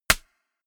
Royalty free music elements: Percussion